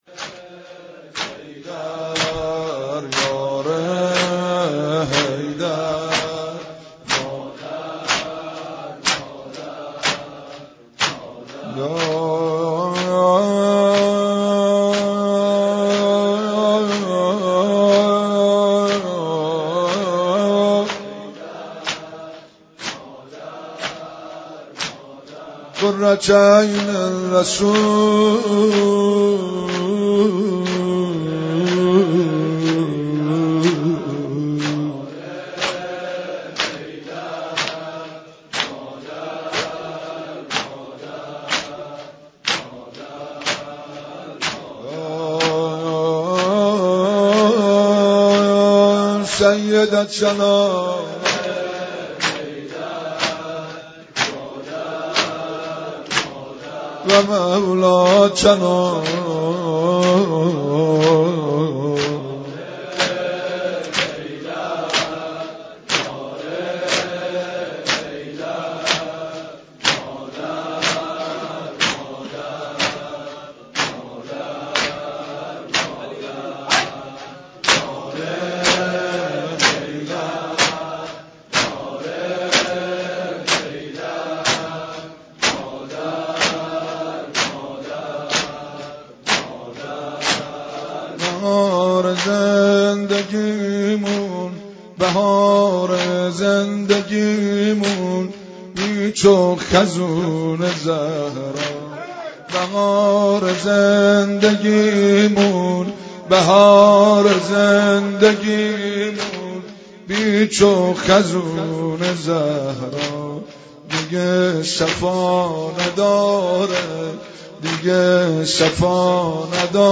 نوحه خوانی و سیبنه زنی به مناسبت ایام فاطمیه